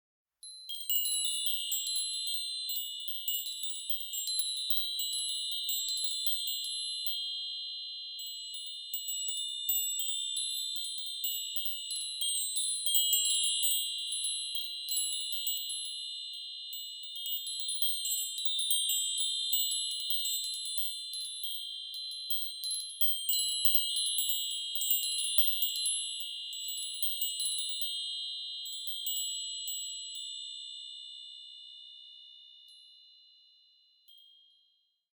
Meinl Sonic Energy Mini Chakra Chime 12"/30 cm - Silber (CC12MINI)